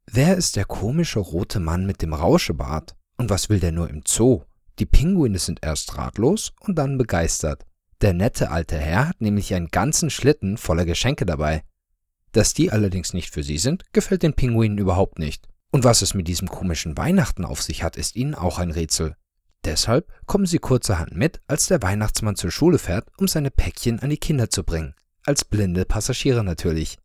German native speaker with more than 7 years of professional Voice Over experience for all your audio needs.
Sprechprobe: Sonstiges (Muttersprache):